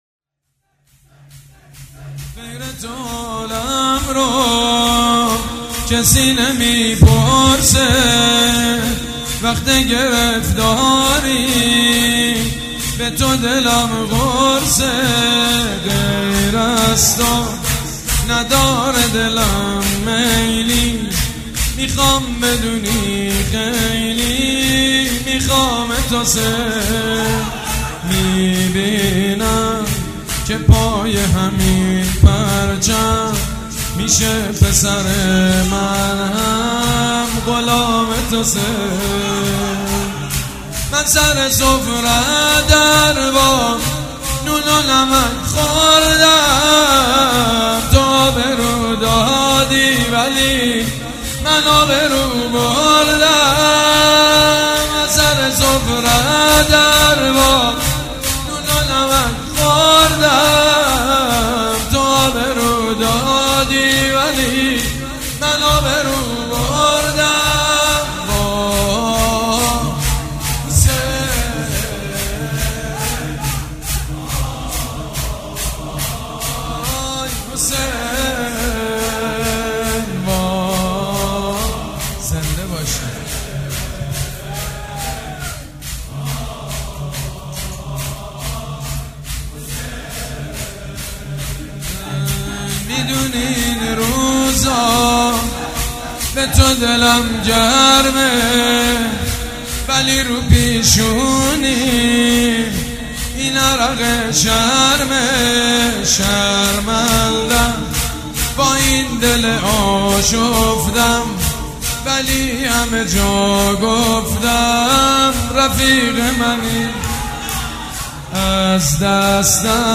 «فاطمیه 1396» شور: غیر تو حالمو کسی نمیپرسه
«فاطمیه 1396» شور: غیر تو حالمو کسی نمیپرسه خطیب: سید مجید بنی فاطمه مدت زمان: 00:05:01